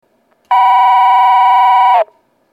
２番線発車ベル
（長野より）   長野より（跨線橋付近）の電子電鈴での収録です。